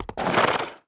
horse_stop.wav